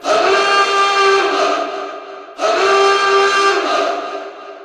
alarmKlaxonLoop.ogg